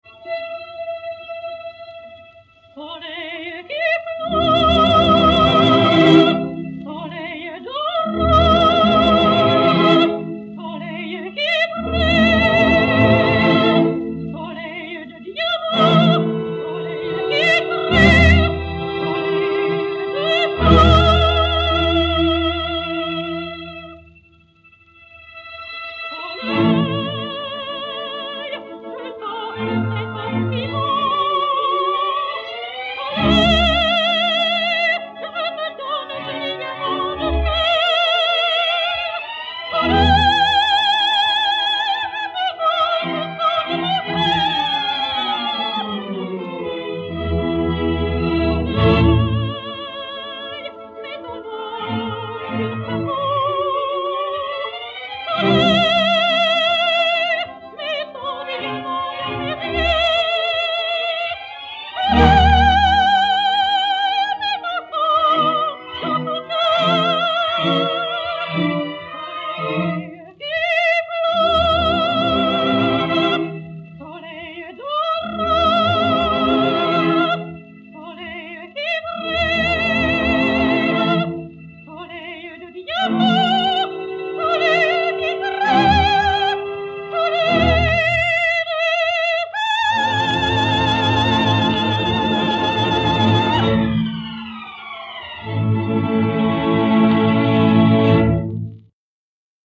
Columbia RF 75 mat. CL 5143-1, enr. en 1931